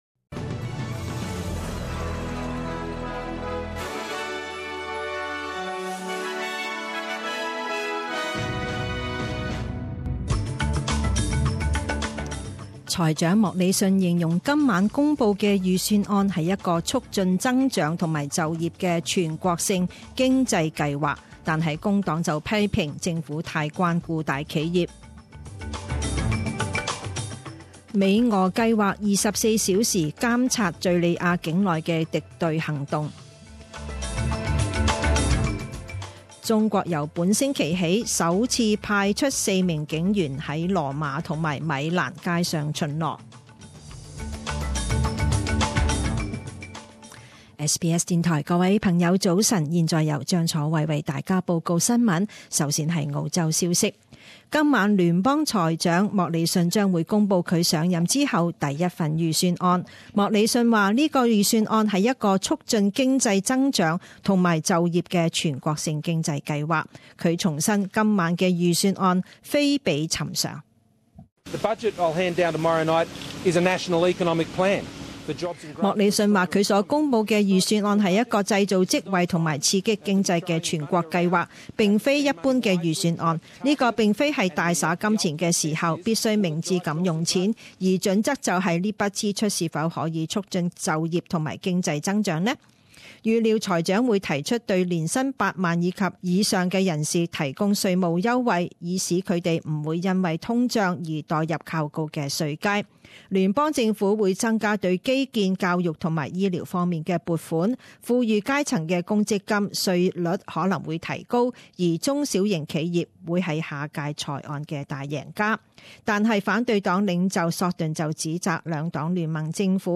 五月三日十点钟新闻报导